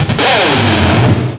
pokeemerald / sound / direct_sound_samples / cries / krookodile.aif
krookodile.aif